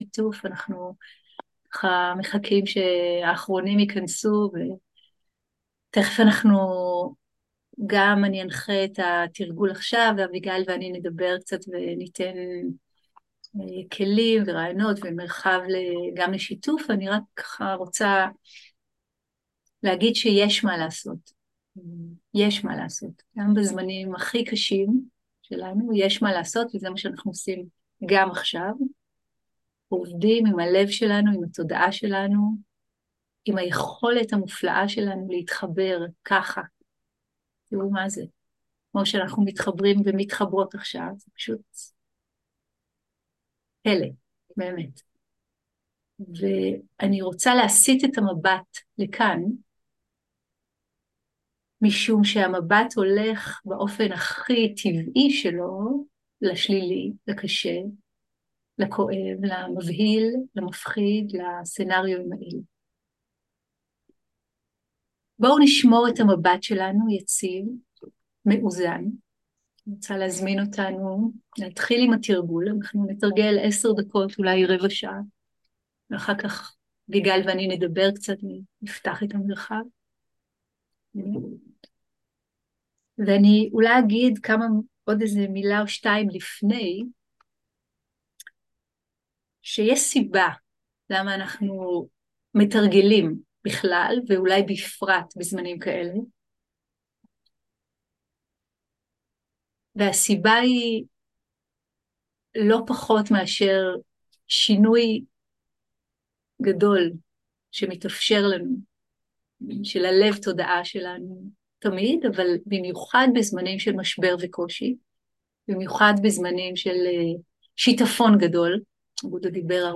Dharma talk language